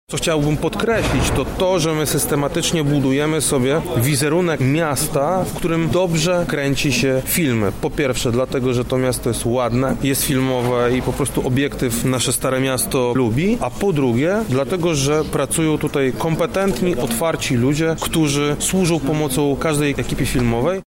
Lublin dzięki tej współpracy zyskuje efekty wizerunkowe, promocyjne i marketingowe – mówi Krzysztof Komorski, zastępca Prezydenta Miasta Lublin.